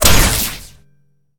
ogg / general / combat / weapons / default_shootable / metal2.ogg
metal2.ogg